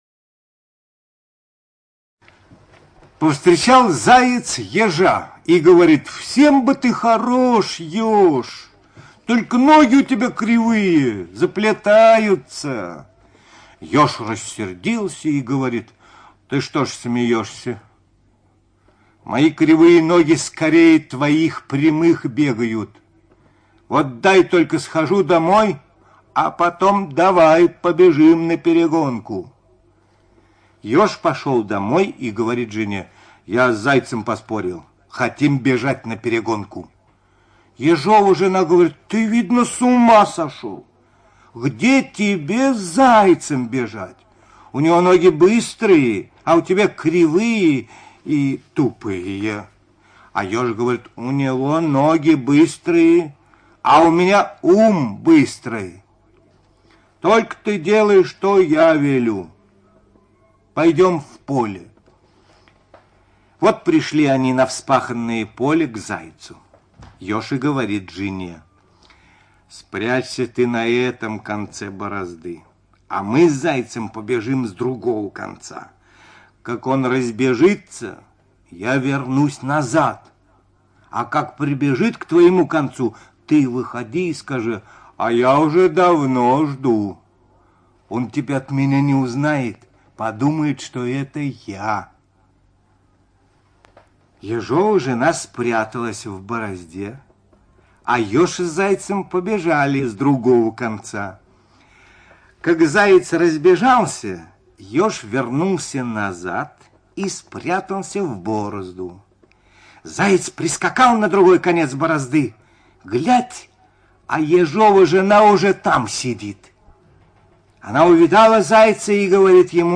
ЧитаетОрлов Д.